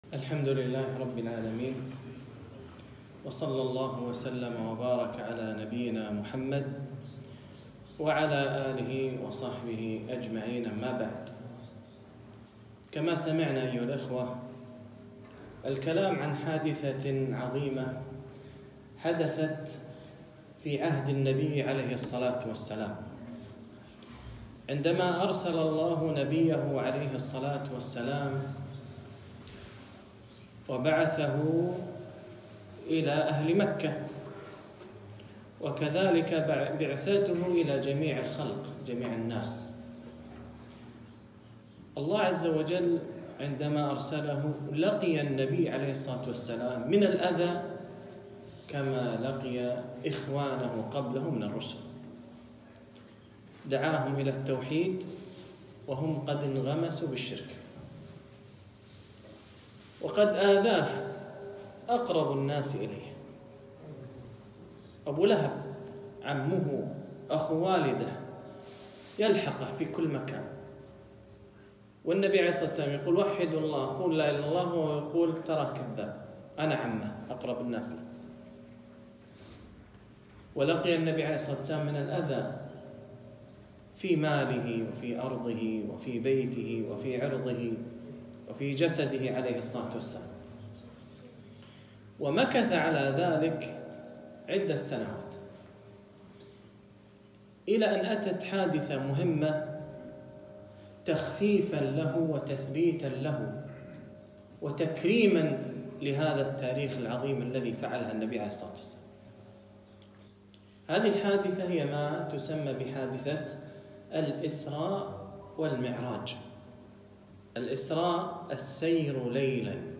محاضرة - فوائد من حادثة الإسراء والمعراج